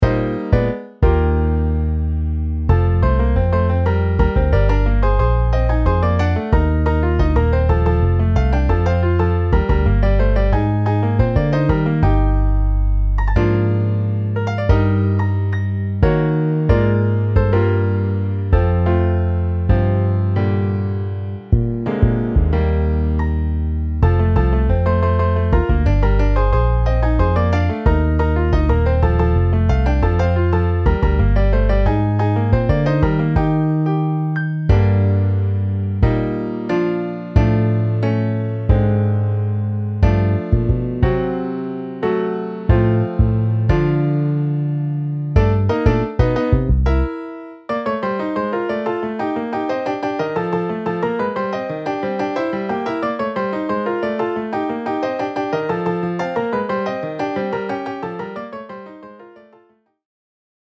Alto Sax, 2X Trumpets, 2X Trombones, Piano, Bass